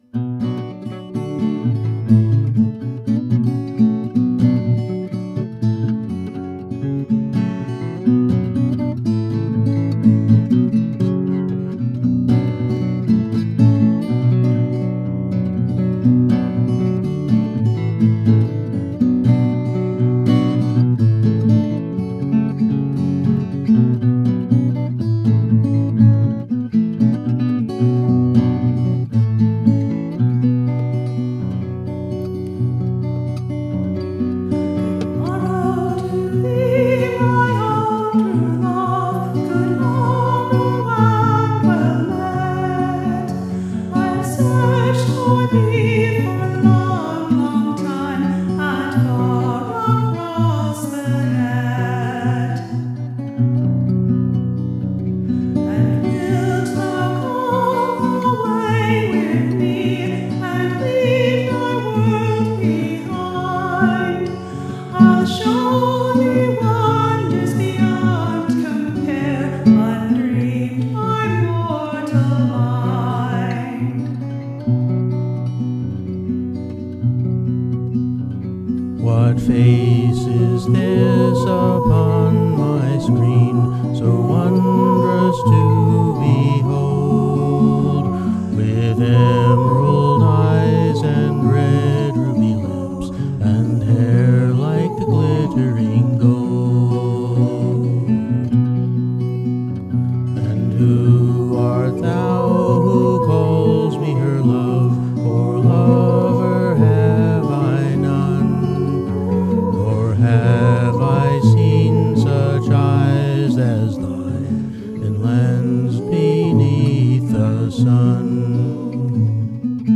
Part (1) uses the high melody; Part (2) the low.